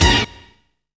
STRING HIT 1.wav